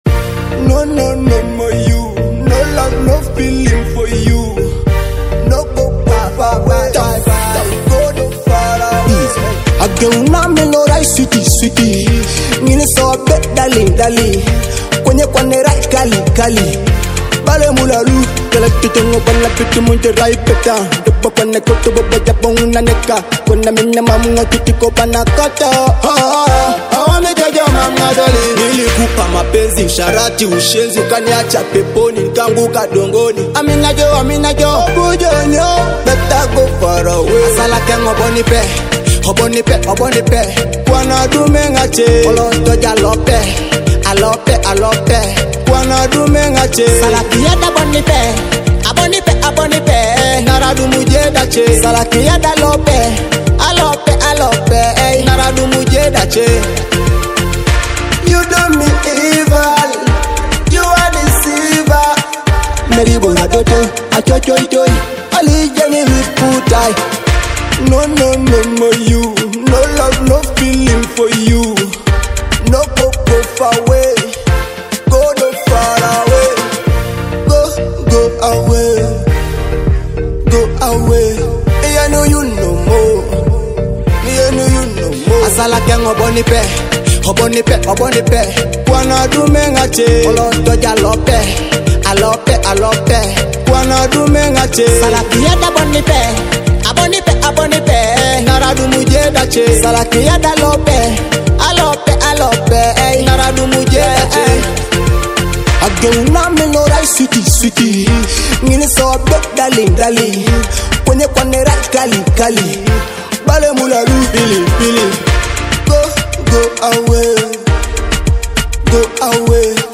This track blends unique rhythms and captivating lyrics
Perfect for fans of Afrobeat and contemporary world music